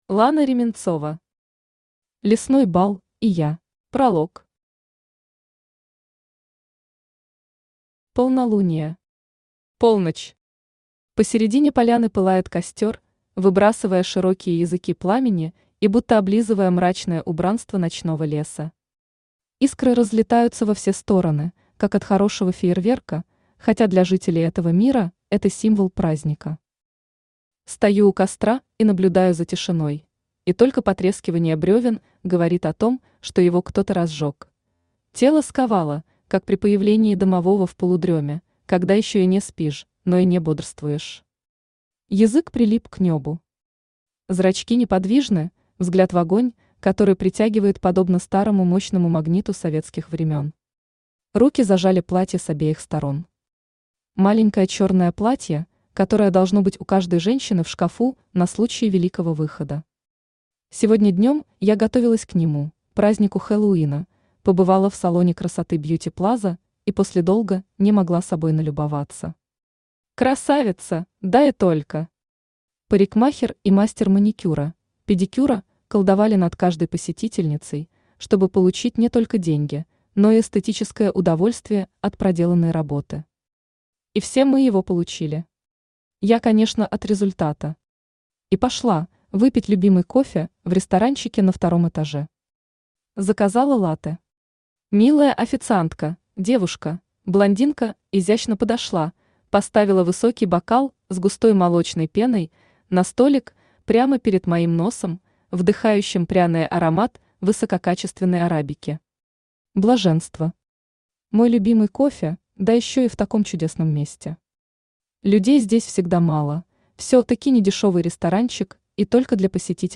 Аудиокнига Лесной бал и я | Библиотека аудиокниг
Aудиокнига Лесной бал и я Автор Лана Александровна Ременцова Читает аудиокнигу Авточтец ЛитРес.